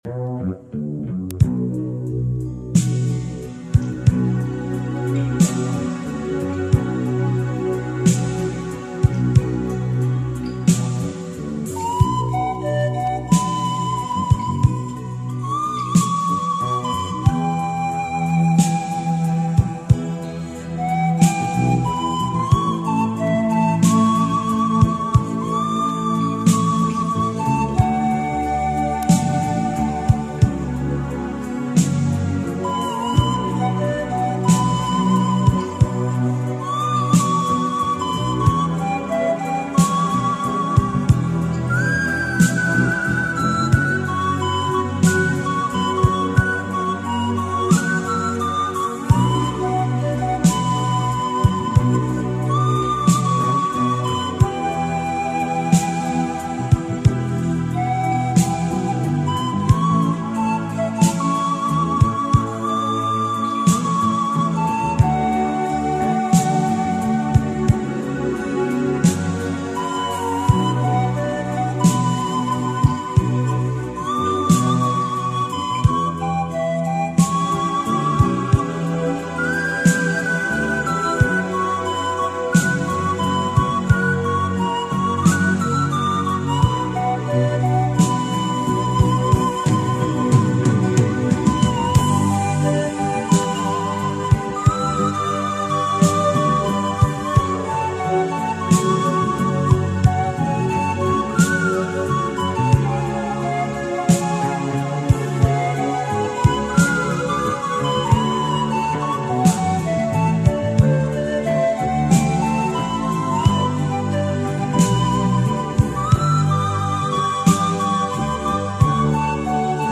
Во вложении неизвестная мне медленная композиция, очень понравилась, слушайте с удовольствием